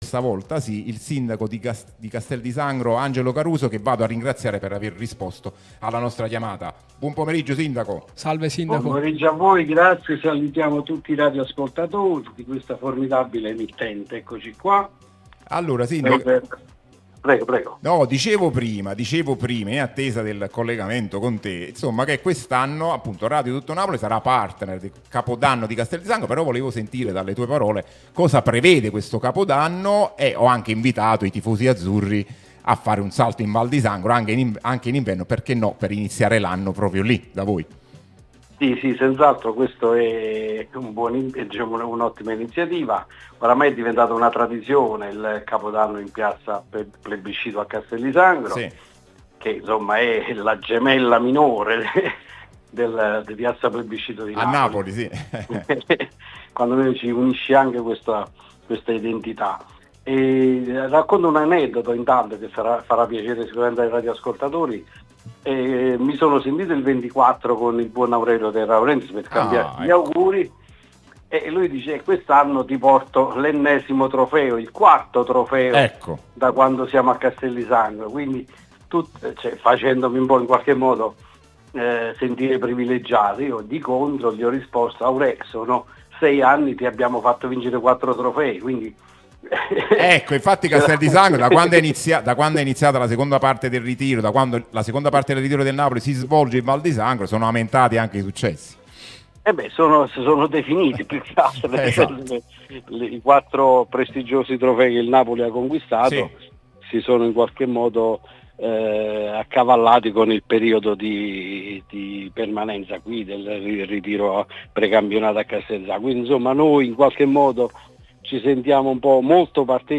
Angelo Caruso, sindaco di Castel di Sangro, è intervenuto nel corso di "Cronache Azzurre" sulla nostra Radio Tutto Napoli, prima radio tematica sul Napoli, in onda tutto il giorno, che puoi ascoltare/vedere qui sul sito o sulle app (qui per Iphone/Ipad o qui per Android): "Capodanno a Castel di Sangro?